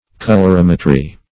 Colorimetry \Col`or*im"e*try\, n. [See Colorimeter.]